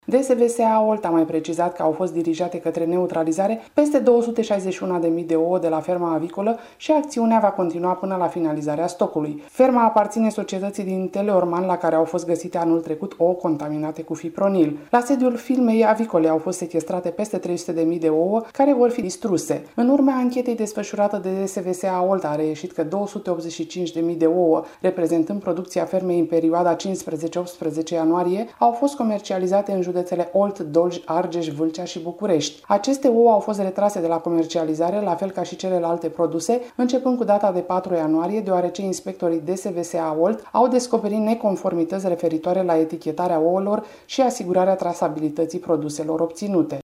Ouăle contaminate proveneau de la o fermă avicolă din Scorniceşti, judeţul Olt, care acum nu mai are voie să îşi comercializeze produsele. Corespondenta RRA